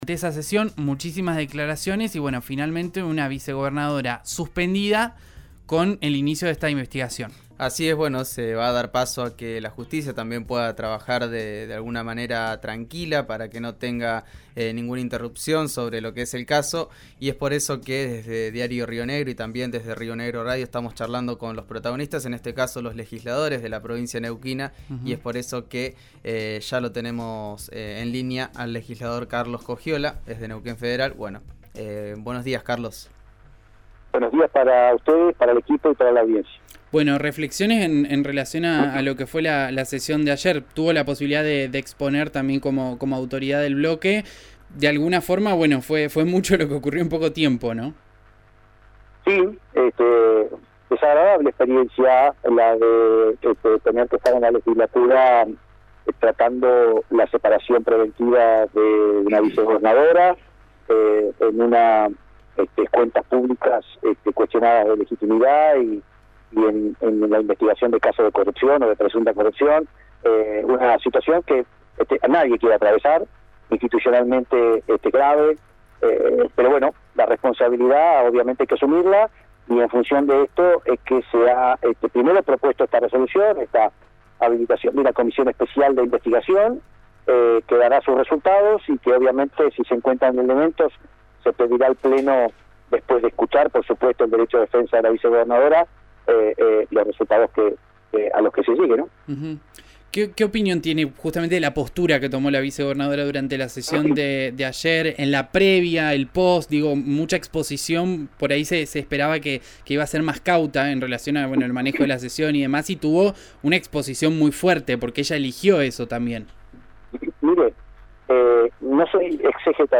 Carlos Coggiola, diputado provincial por Neuquén Federal dialogó con RÍO NEGRO RADIO tras la primera reunión de la comisión investigadora.